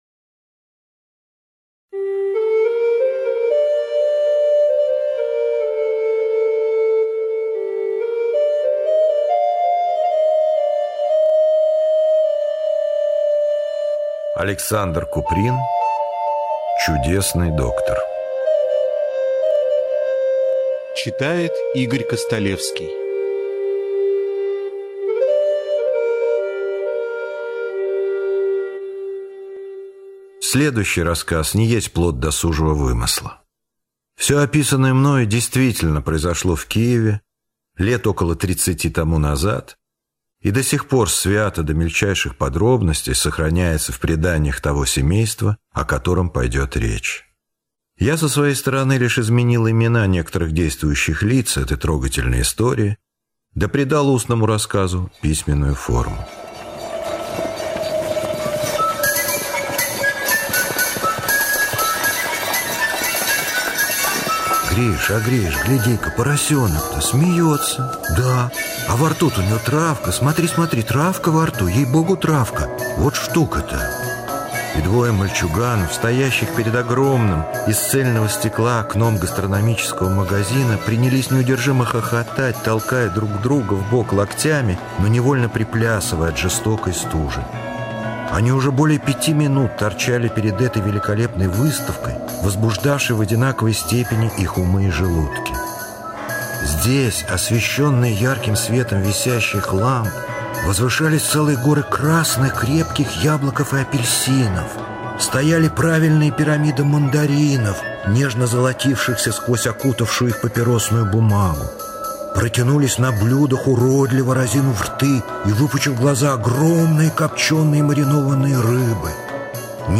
Чудесный доктор - аудио рассказ Куприна А.И. Незнакомый доктор чудесным образом помог семье, попавшей в крайне тяжелой положение.